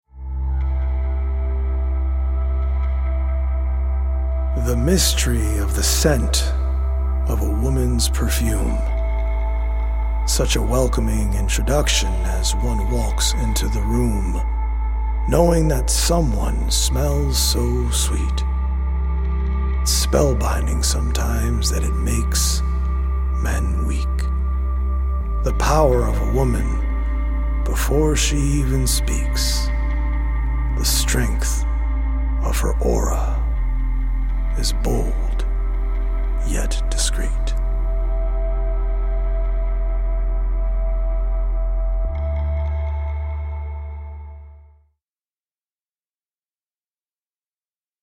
poetic journey
healing Solfeggio frequency music